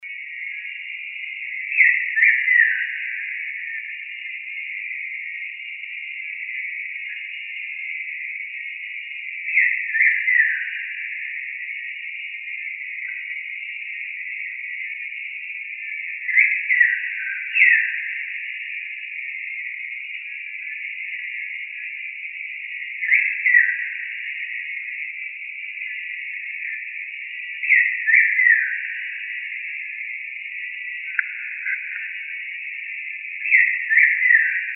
Bico-de-pimenta (Saltator fuliginosus)
Nome em Inglês: Black-throated Grosbeak
Localidade ou área protegida: Bio Reserva Karadya
Condição: Selvagem
Certeza: Gravado Vocal